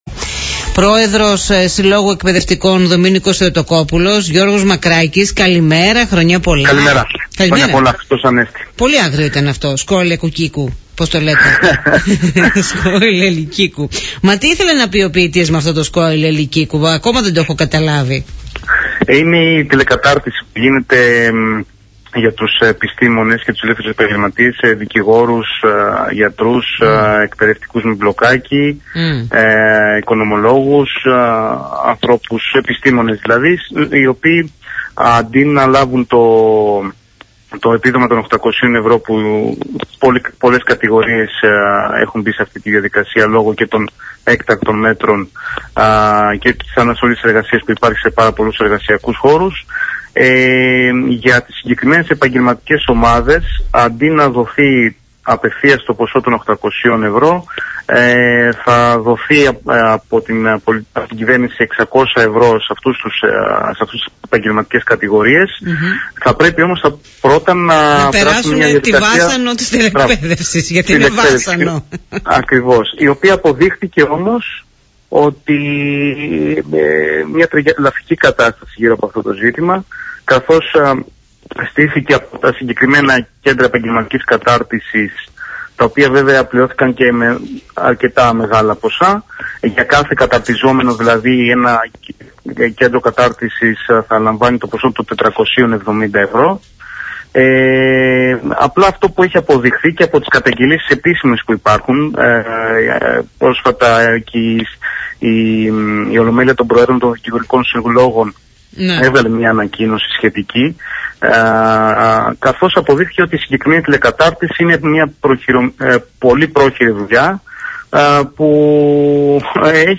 μίλησε στον Politica 89.8